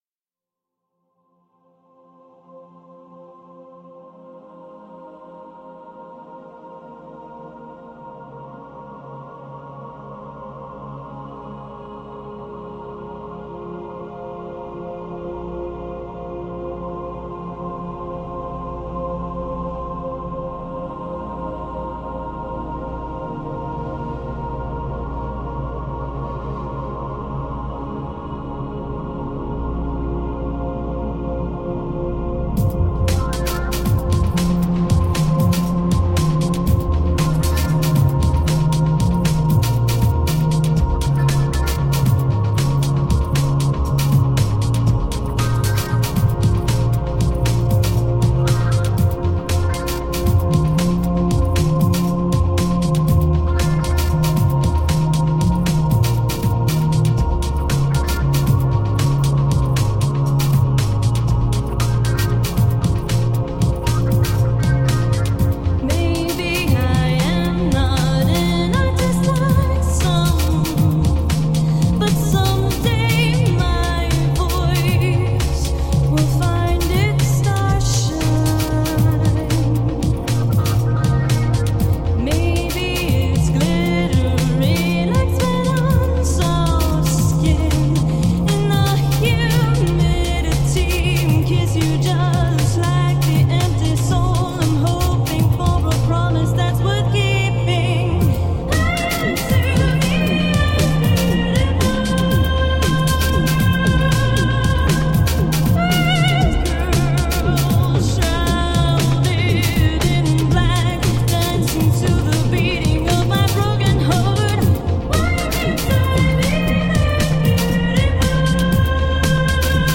Haunting, ethereal pop ambient.
beautifully emotive female vocals
Tagged as: Ambient, Rock, Pop, Electro Rock, Ethereal